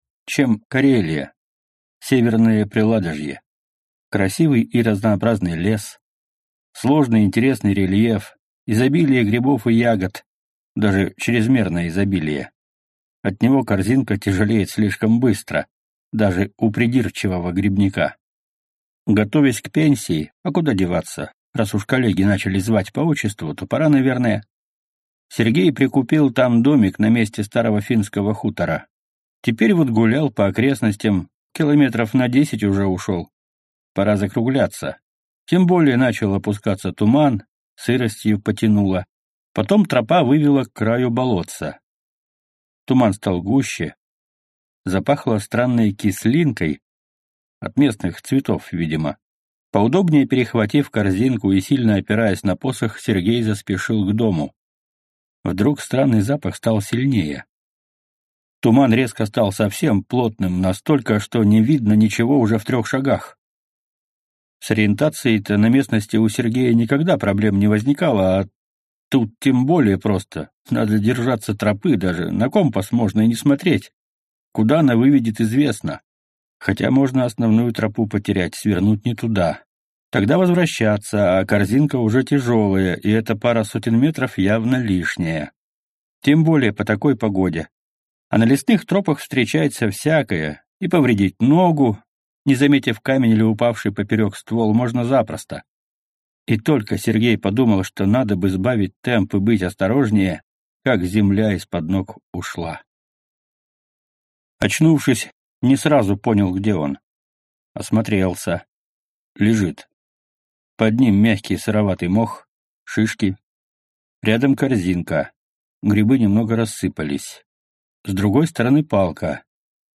Аудиокнига S-T-I-K-S. Грибы с зубами | Библиотека аудиокниг